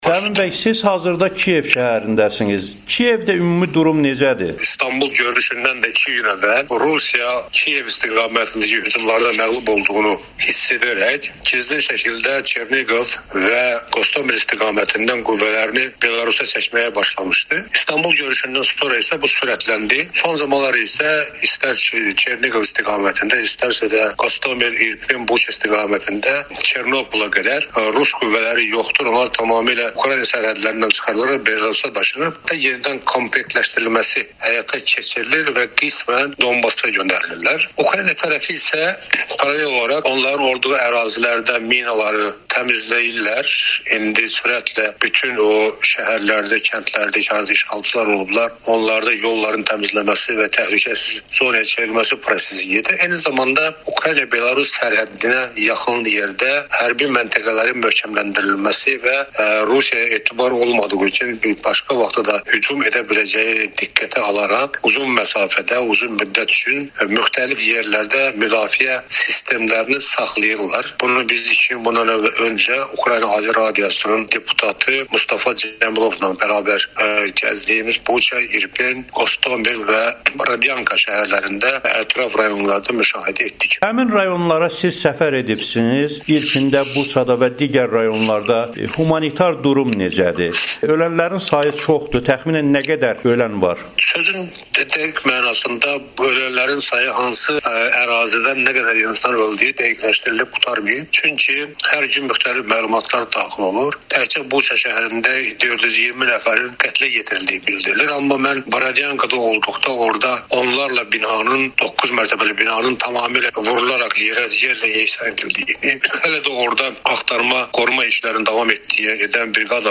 Bu barədə Azərbaycanın Daxili Qoşunlarının keçmiş komandanı, hazırda Kyivdə yaşayan Fəhmin Hacıyev Amerikanın Səsinə müsahibsində bildirib.